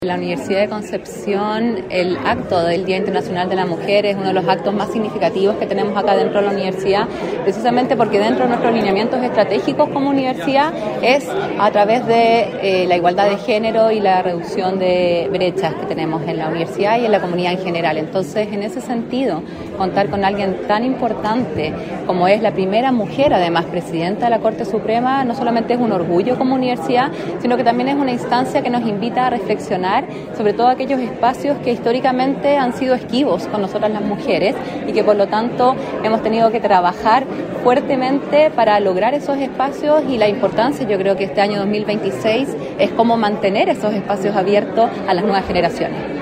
Presidenta de la Corte Suprema encabezó acto de conmemoración del 8M en la UdeC - Radio UdeC